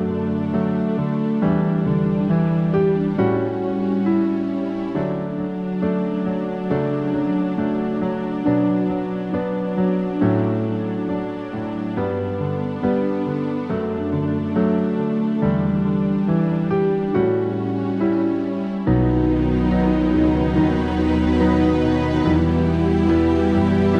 No Backing Vocals Easy Listening 3:05 Buy £1.50